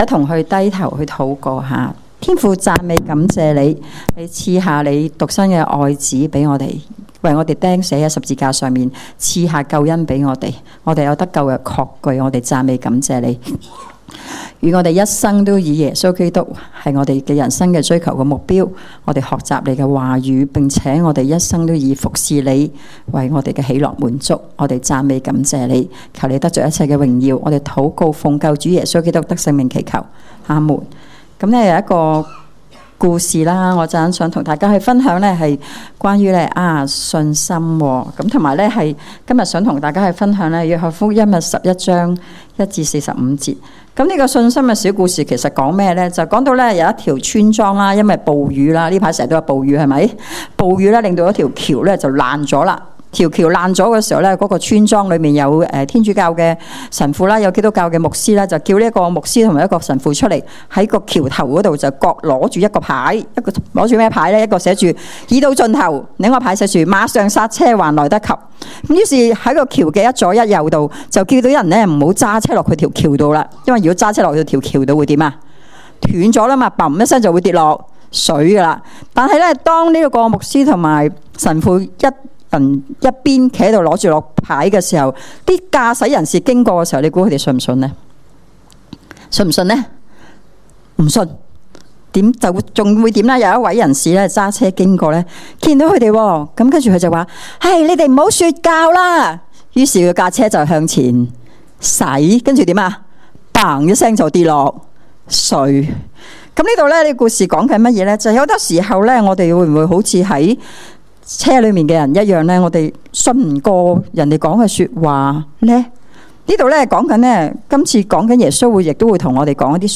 證道重溫
恩福元朗堂崇拜-早、中堂